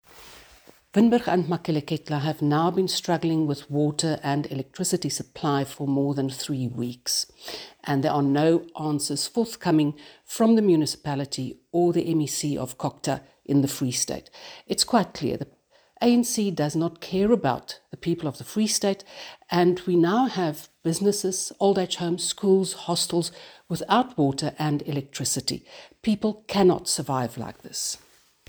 Afrikaans soundbites by Annelie Lotriet MP.